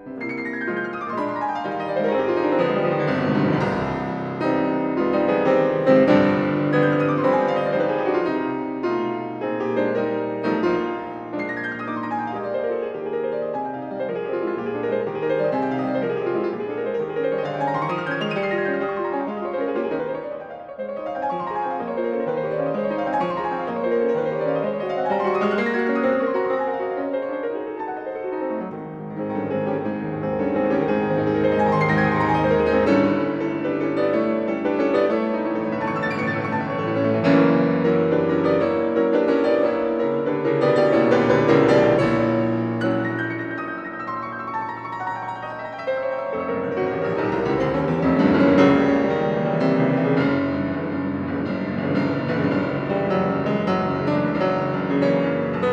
是鋼琴家20到30歲之間，不露鋒芒，從容而優雅。